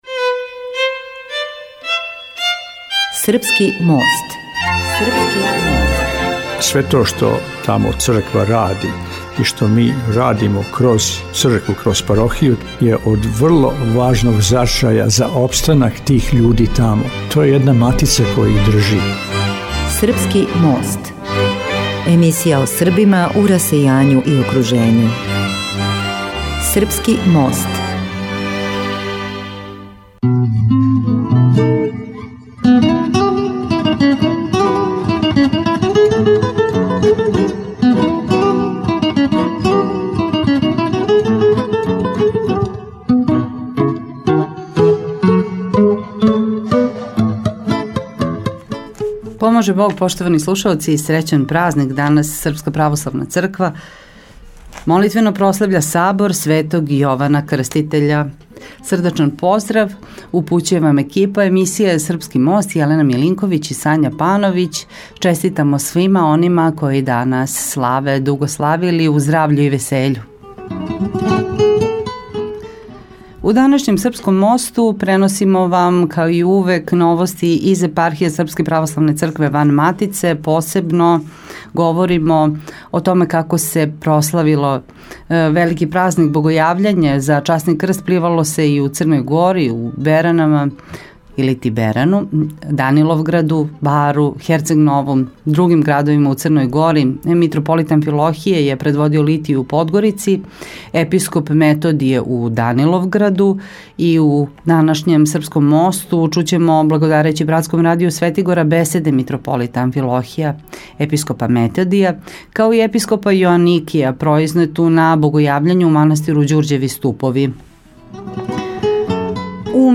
На Богојављење 19. јануара 2020.г. Митрополит Амфилохије је предводио литију у Подгорици, а Епископ Методије у Даниловграду. Слушамо, благодарећи братском радију Светигора, беседе Митрополита Амфилохија, Епископа Методија, као и Епископа Јоаникија, произнету на Богојављење у манастиру Ђурђеви Ступови.